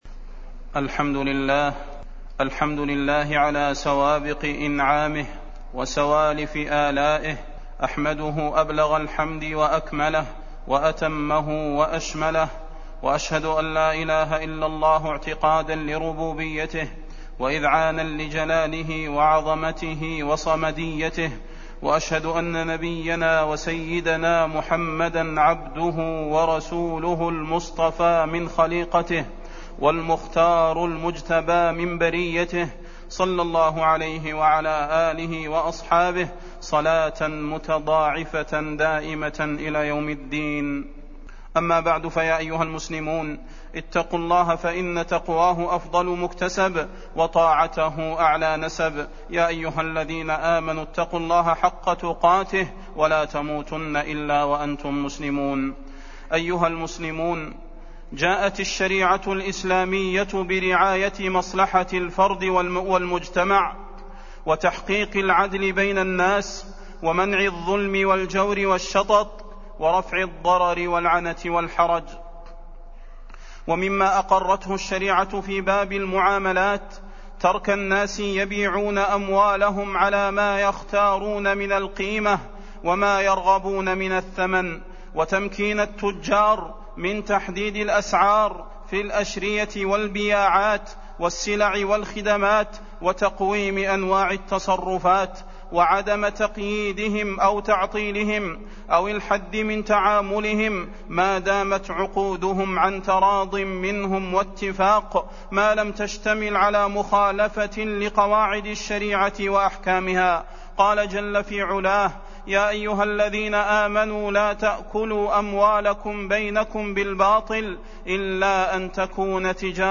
تاريخ النشر ١٨ ربيع الأول ١٤٢٨ هـ المكان: المسجد النبوي الشيخ: فضيلة الشيخ د. صلاح بن محمد البدير فضيلة الشيخ د. صلاح بن محمد البدير دعوا الناس يرزق الله بعضهم من بعض